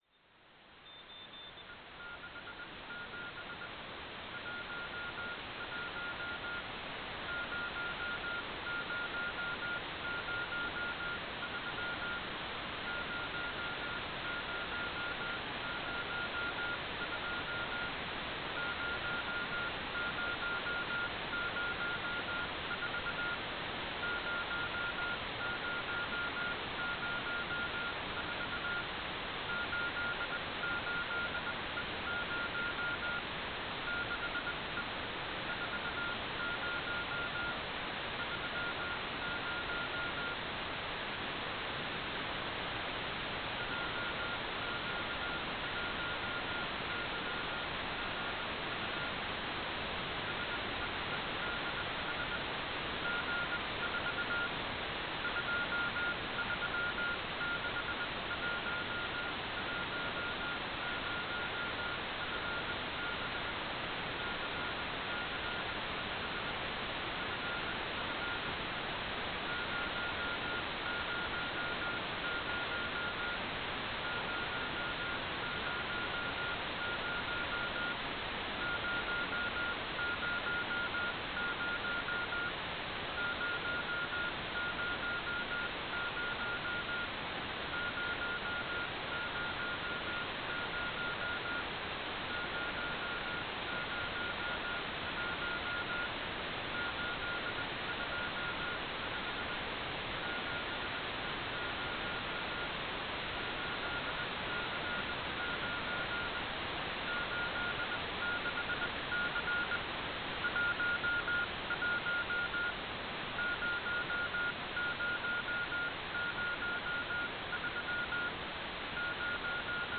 "transmitter_mode": "CW",